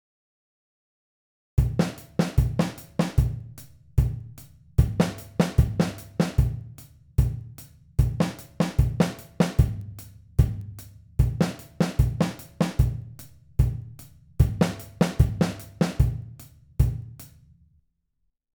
Patterns A and B mean that we have to play the kick and hi-hat together on beat 1, the snare on the “e”, the hi-hat on the “and”, just the snare on the “a”.
Patterns C and D mean that we have to play the kick and hi-hat together on beat 3, nothing on the “e”, the hi-hat on the “and”, nothing on the “a”.